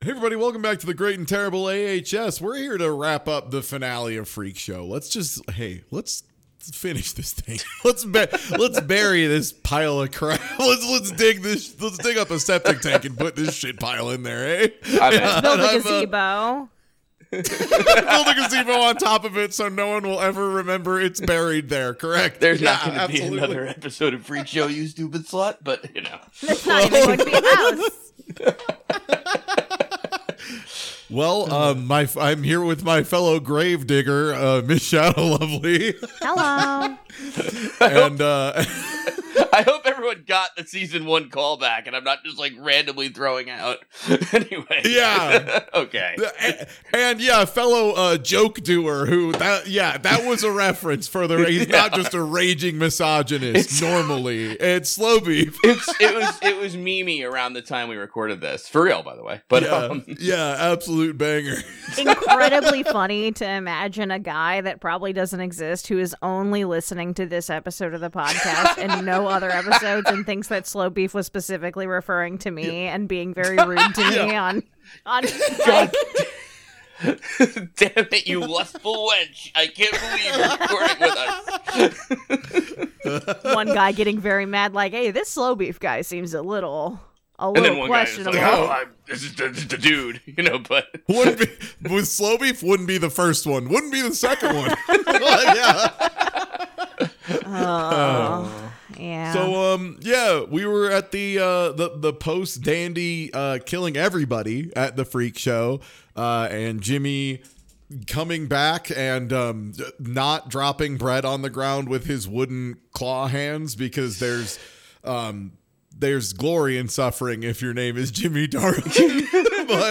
It's a really good movie, we all agree. REUPLOADED WITH FIXED AUDIO.